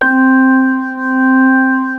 B3-ORGAN 2.wav